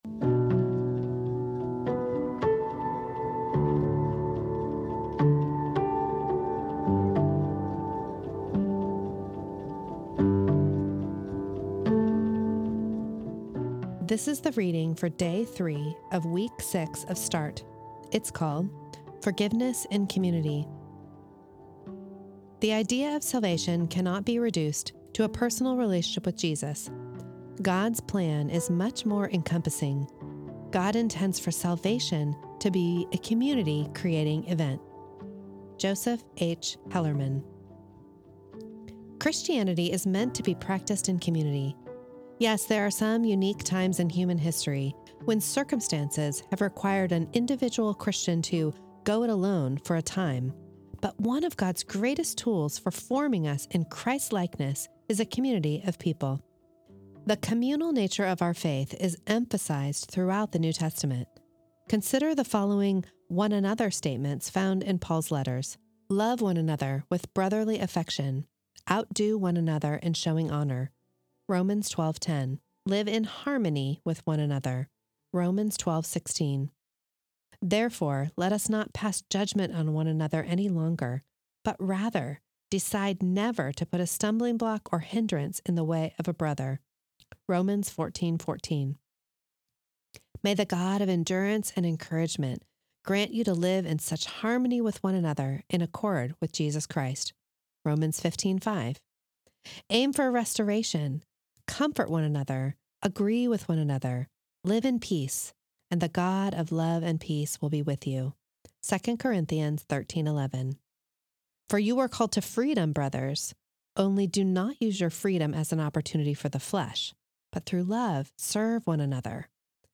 This is the audio recording of the third reading of week eight of Start, entitled Forgiveness in Community.